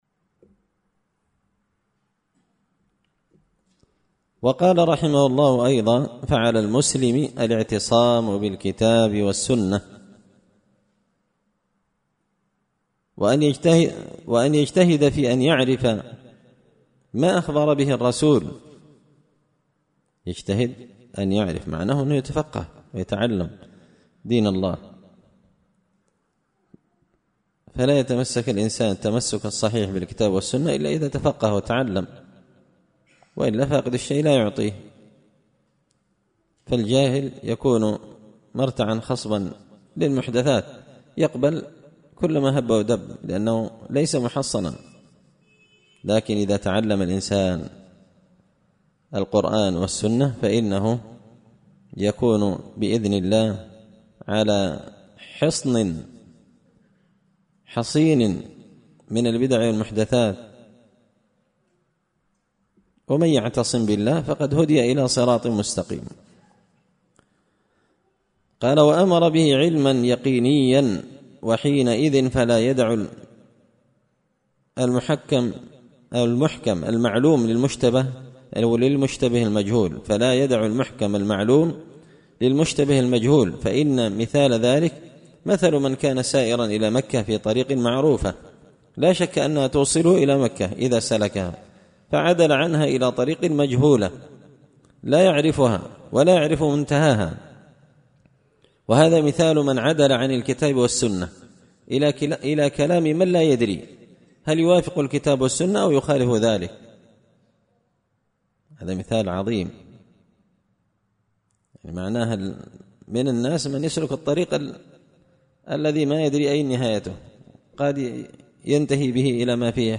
شرح لامية شيخ الإسلام ابن تيمية رحمه الله _الدرس 21